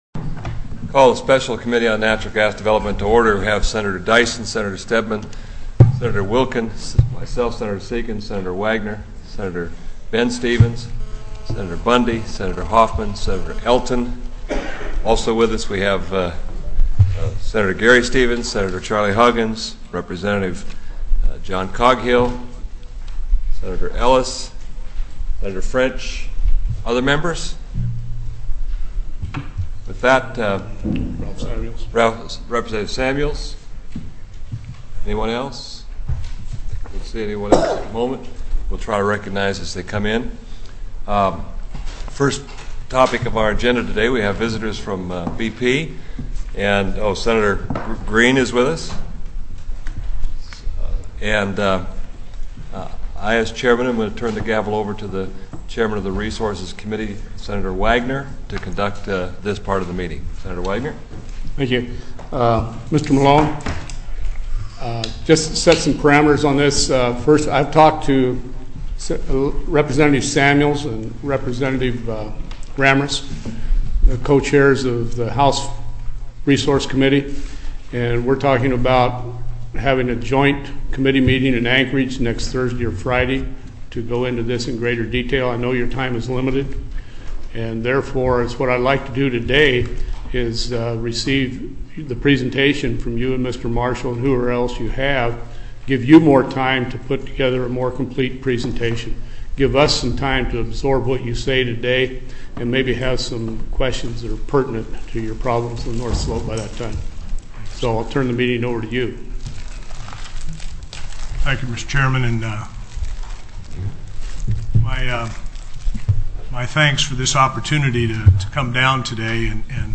08/07/2006 01:00 PM Senate SPECIAL COMMITTEE ON NATURAL GAS DEV